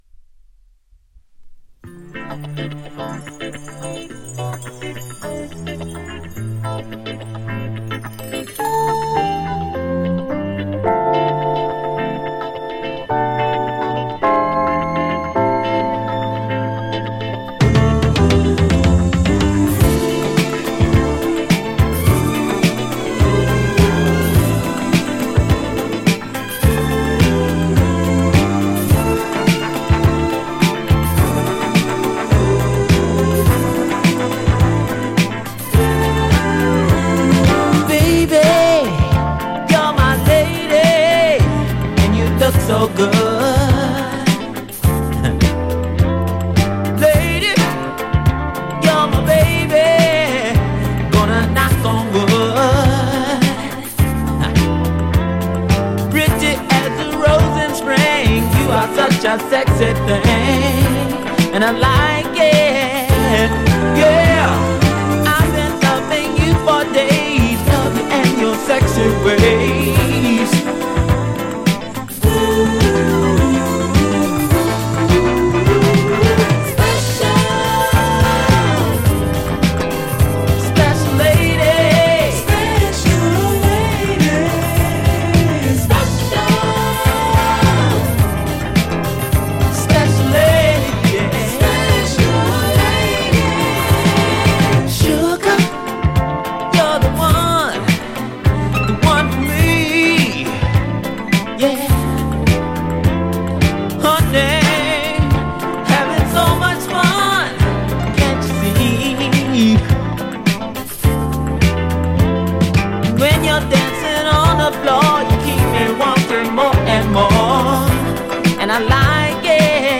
ジャンル(スタイル) SOUL / FUNK / DISCO